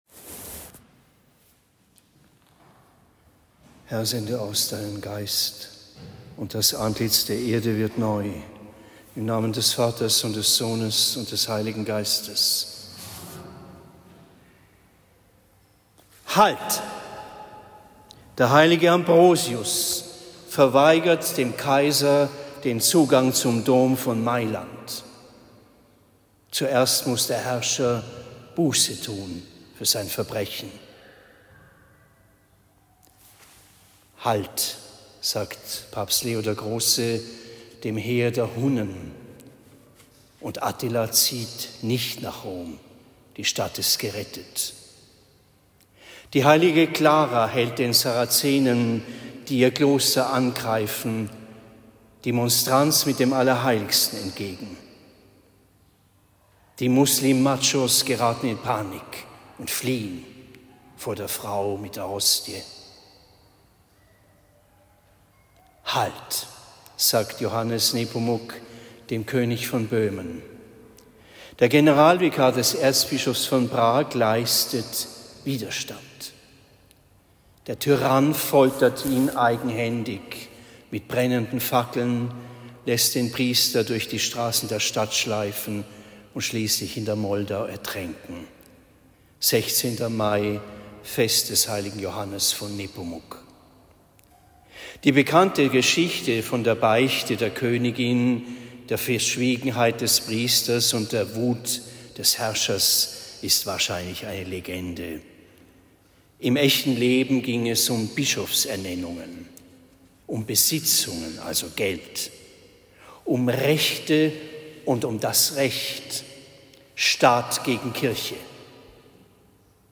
Predigt am 16. Mai 2024 in Marktheidenfeld St. Laurentius